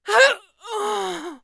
Sound / sound / monster / bksecond / dead_1.wav
dead_1.wav